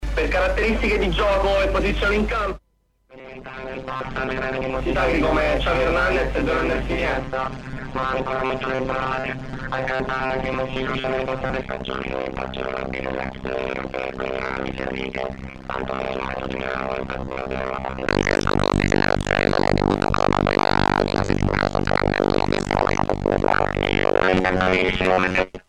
Korg DVP-1 Vocoder (1986)
Early digital rackmount voice processor: harmonizer, pitch-shifter and vocoder with internal wave-carriers.
The DVP-1 vocoder uses only 8 internal ROM-stored waveforms (all choir-like) as carrier instead of traditional input, this is very frustrating compared to old-school or actual vocoders.
demo DVP1 AUDIO DEMO
Now what I liked: DVP1 is fun for LFO vocal experimentation and glitched sounds"